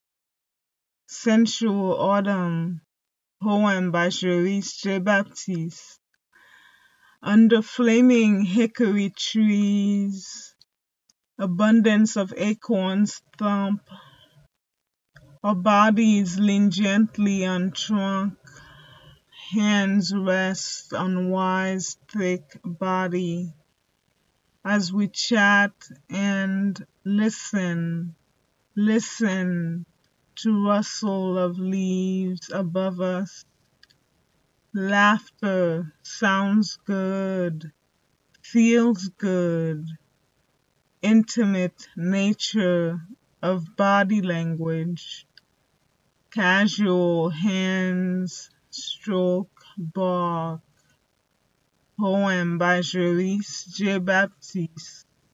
read her poem